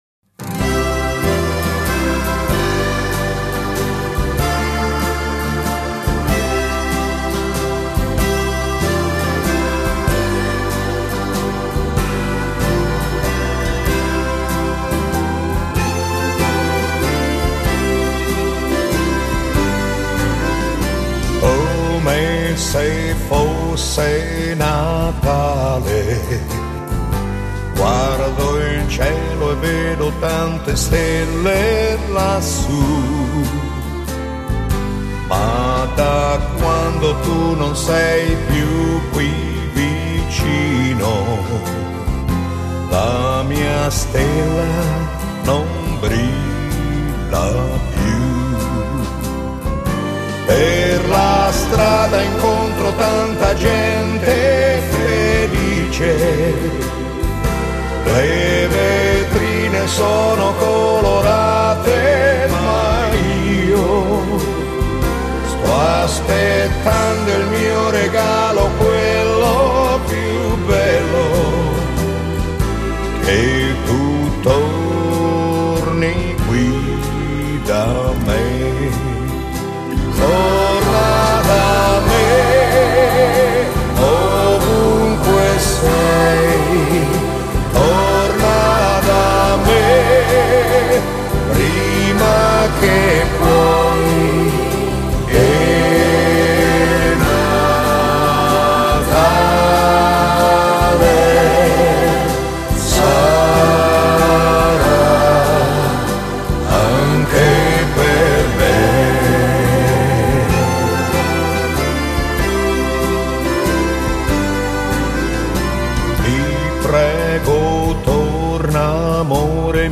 Genere: Valzer lento